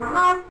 mech_unduck.ogg